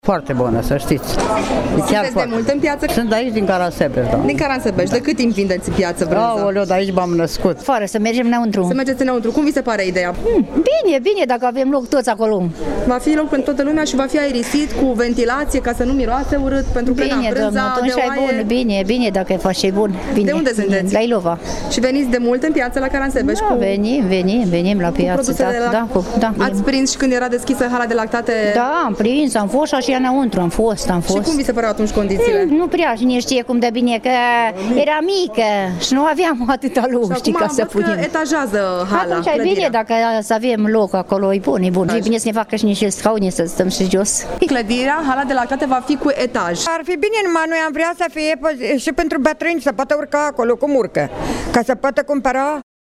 Vânzătorii, care până acum şi-au expus produsele pe tarabă spun că este o investiţie benefică: